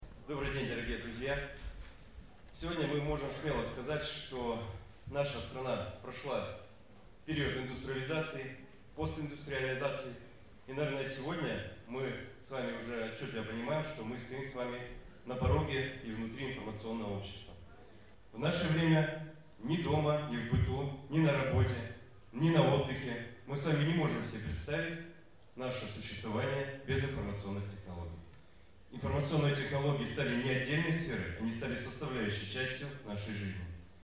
Мероприятие проходит в выставочном комплексе «Русский Дом».
Как сообщает ИА «СеверИнформ», важность проведения этого форума подчеркнул заместитель губернатора Вологодской области.